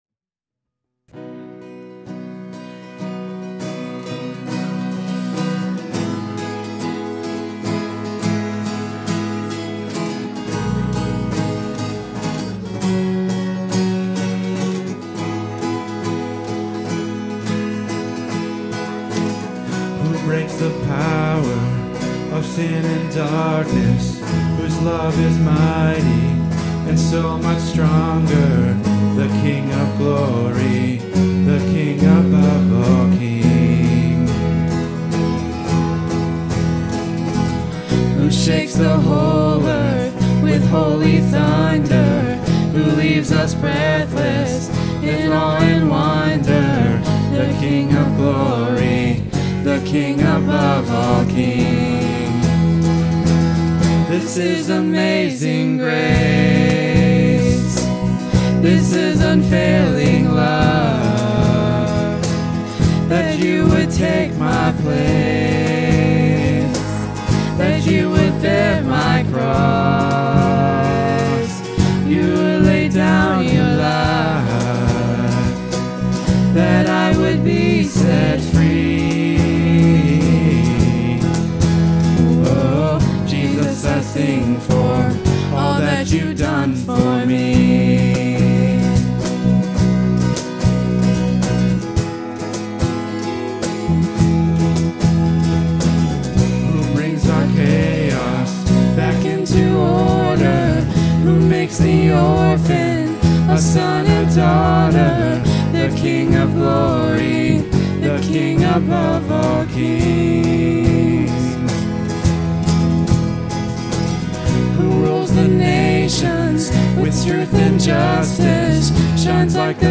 Worship 2024-07-28